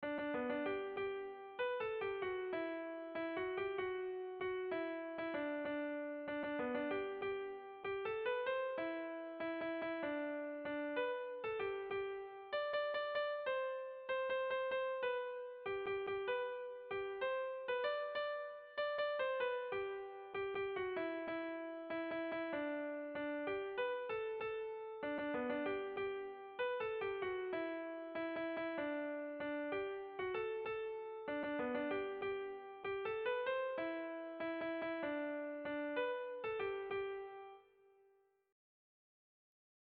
Sentimenduzkoa
Hamarreko handia (hg) / Bost puntuko handia (ip)
ABDEAB